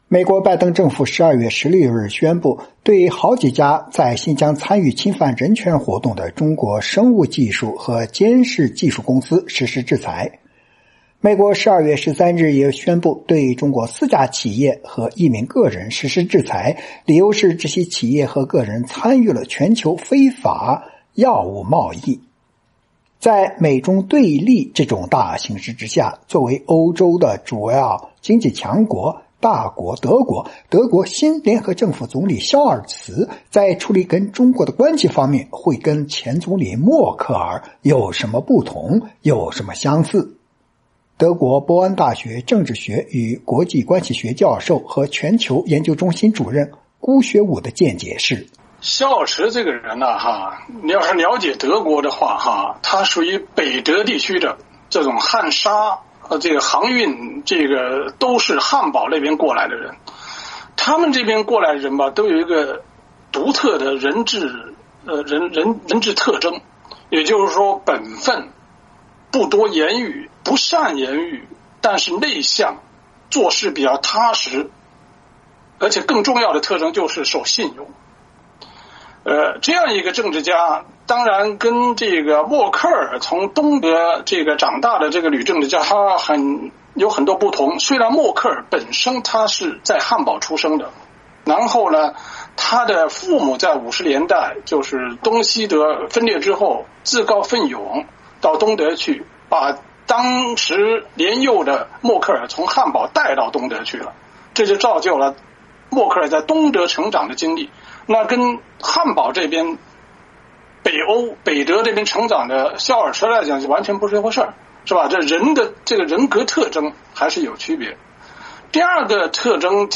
美国之音专访